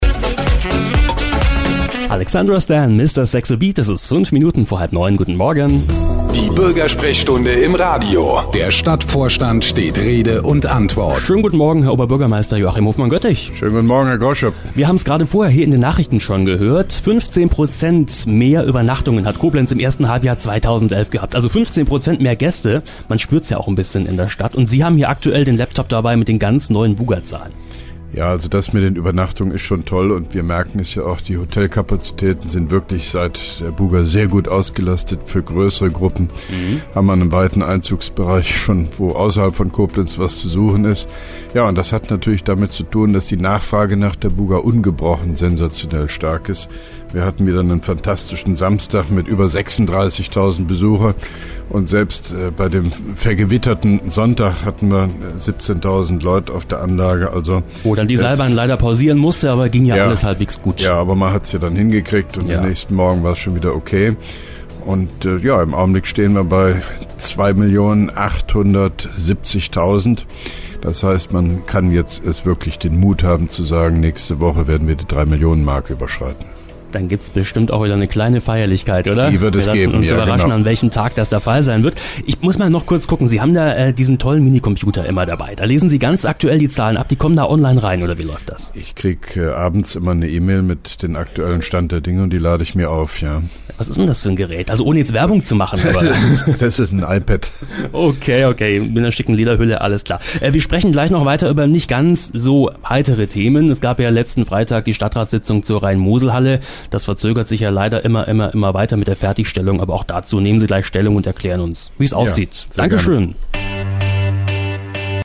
(1) Koblenzer Radio-Bürgersprechstunde mit OB Hofmann-Göttig 13.09.2011
Antenne Koblenz 98,0 am 13.09.2011, ca. 8.25 Uhr (Dauer 01:53 Minuten)